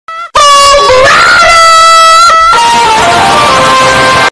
Voicy_hog-rider-EARRAPE.mp3